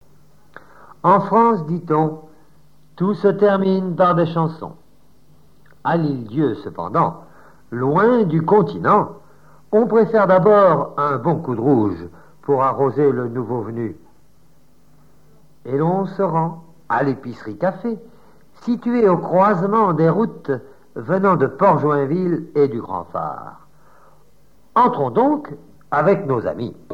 Reportage Folklore vivant - Les chansons
chanteur(s), chant, chanson, chansonnette
reportage et montage sonore relatif à Saint-Sauveur